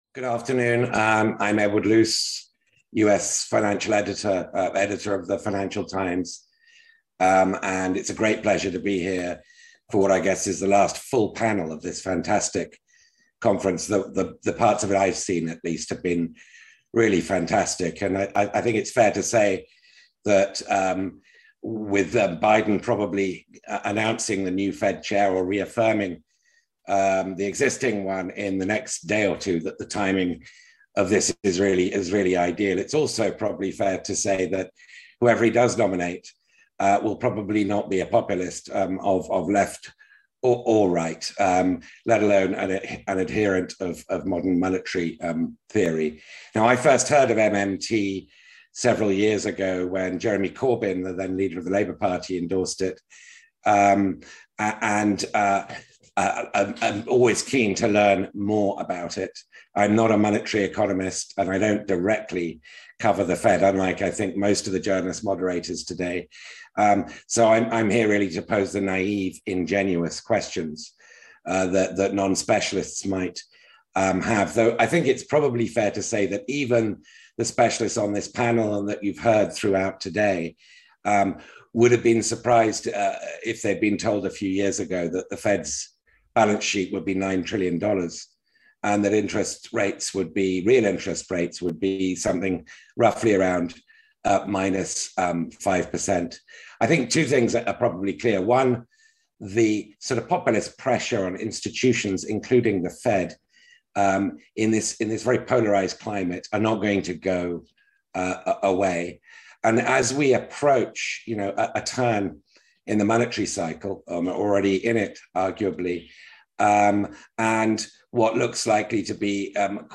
39th Annual Monetary Conference: Panel 4: Helicopter Money and Fiscal QE